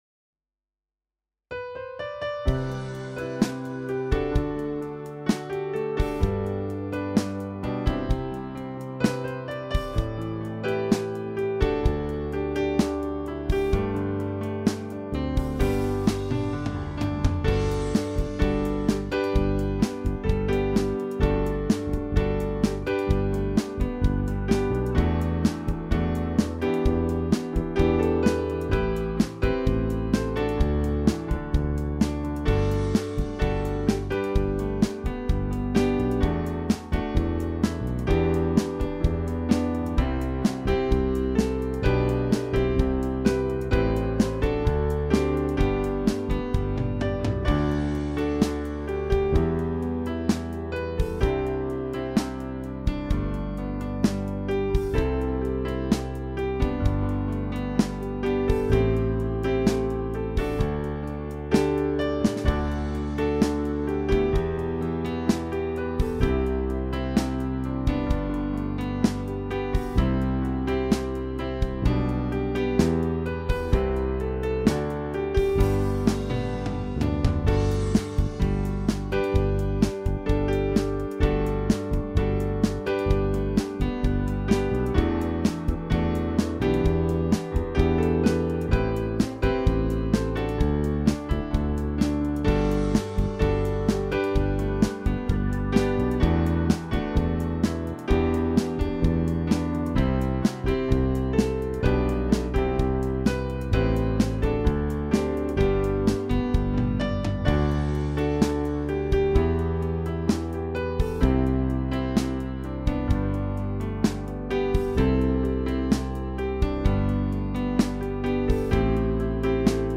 Hörprobe & Download Playback